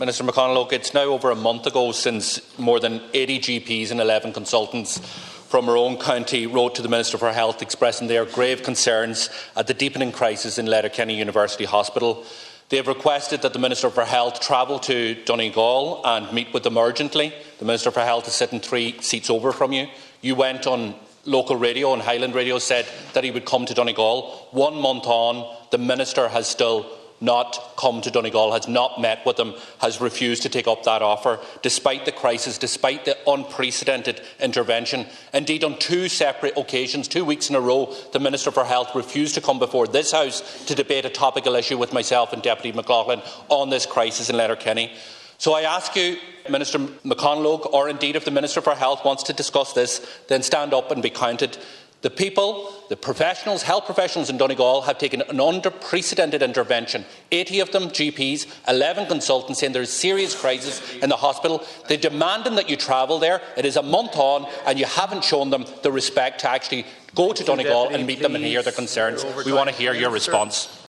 In the Dail a short time ago, Donegal Deputy Pearse Doherty challenged Minister Donnelly to state when he is planning to visit the hospital: